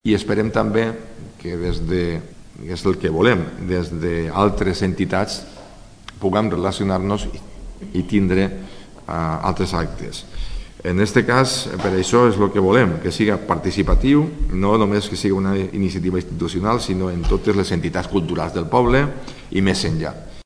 El Ayuntamiento de Alzira ha anunciado hoy en rueda de prensa que 2026, cuando se cumplen 750 años de la muerte del Rey Jaume, será el Año Jaime I. El alcalde de Alzira, Alfons Domínguez junto a los tenientes de alcalde, Gemma Alós i Enrique Montalvá, han explicado hoy que el año que viene Alzira encabeza la conmemoración de esta efeméride.